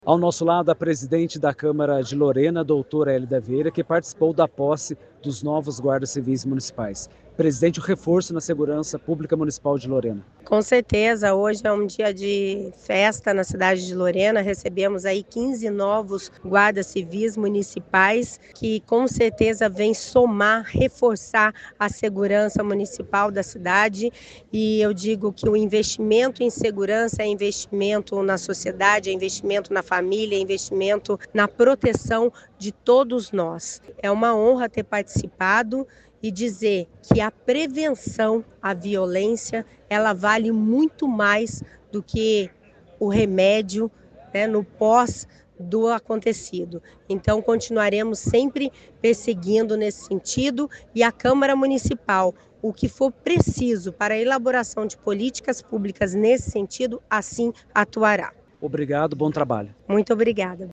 Entrevistas (áudio):